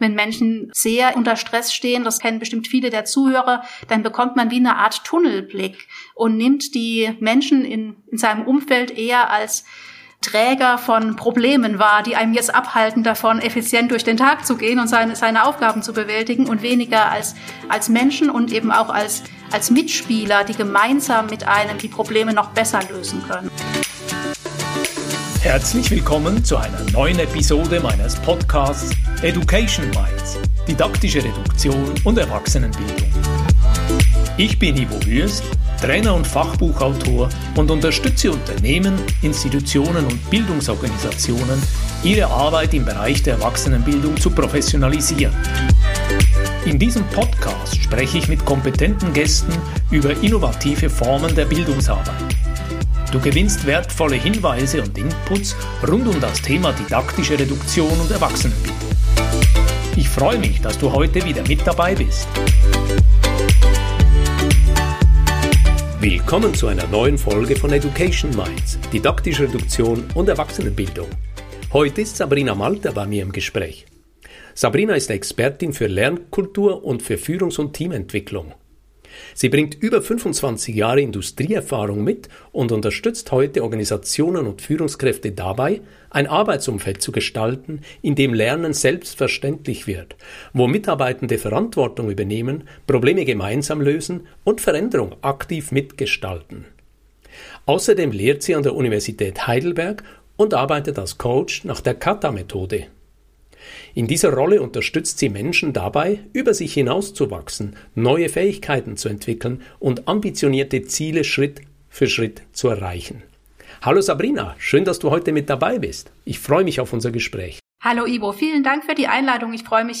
Ein Gespräch über Mut, Neugier und die Kunst, im Wandel beweglich zu bleiben.